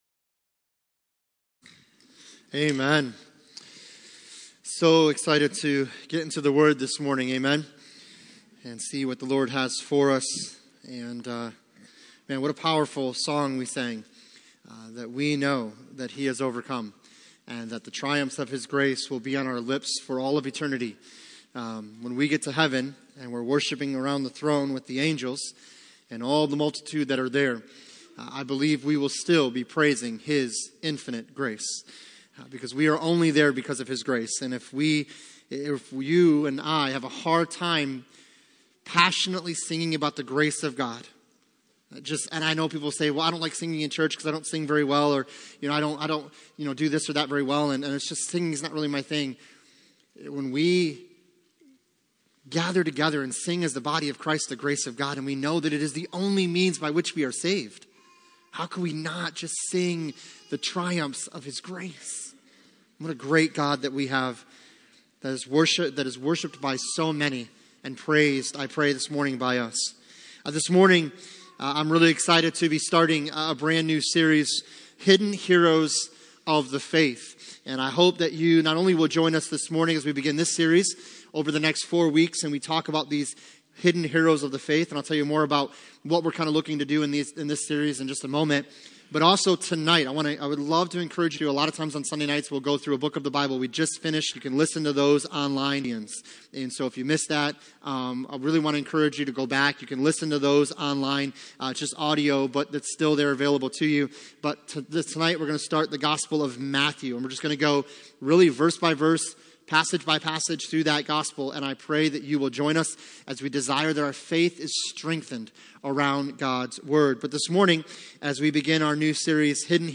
Passage: 2 Kings 5:1-5 Service Type: Sunday Morning